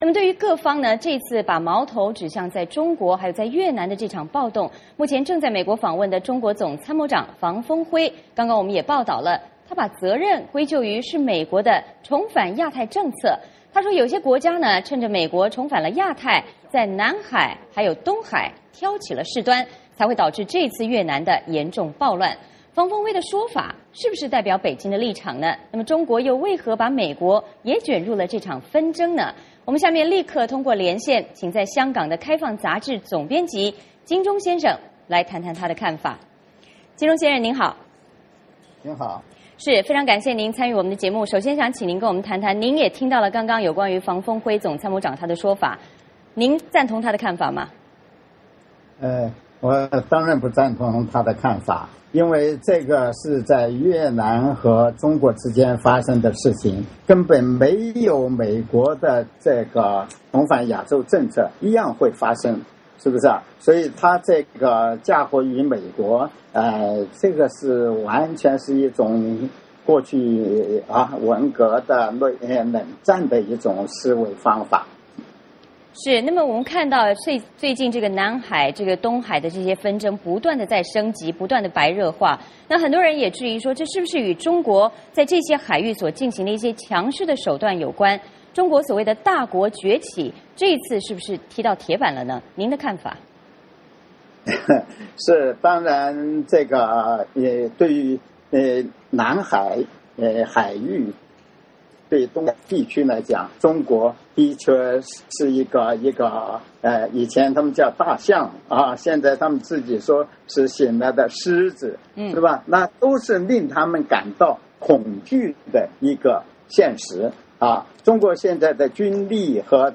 我们通过视频连线